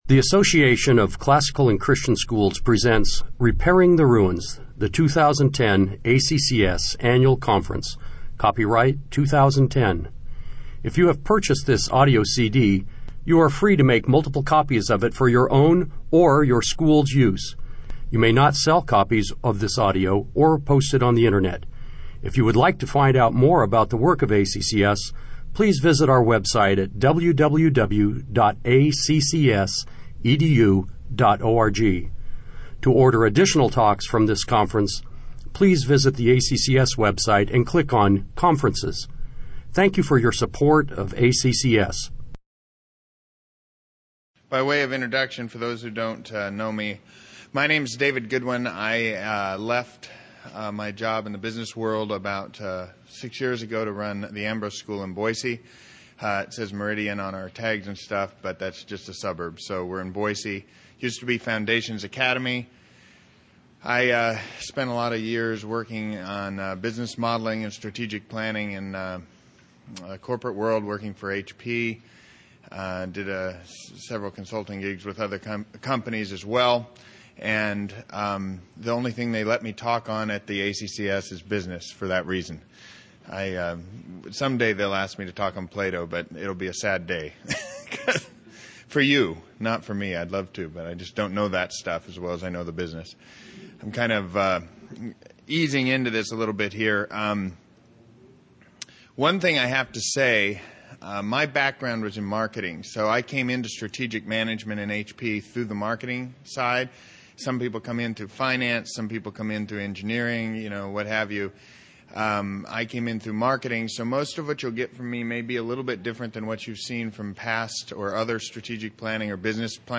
2010 Workshop Talk | 0:55:40 | All Grade Levels, Budgets & Finance, Leadership & Strategic
Jan 28, 2019 | All Grade Levels, Budgets & Finance, Conference Talks, Leadership & Strategic, Library, Media_Audio, Workshop Talk | 0 comments